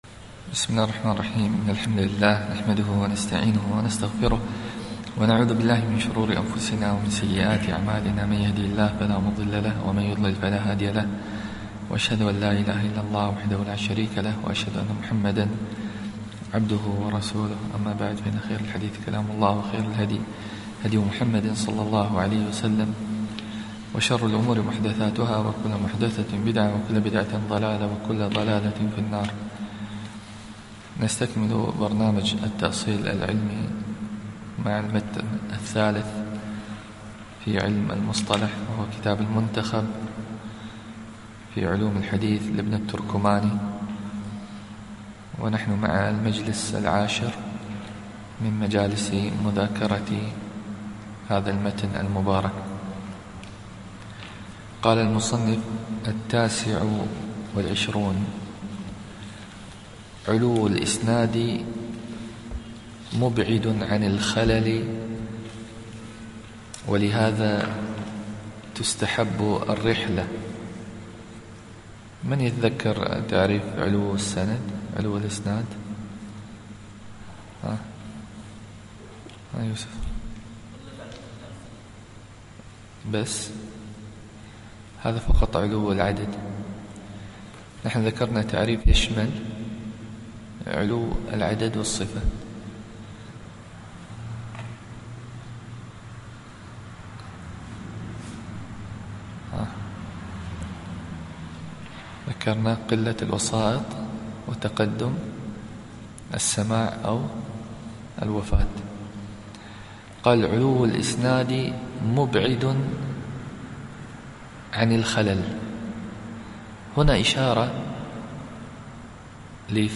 الدرس العاشر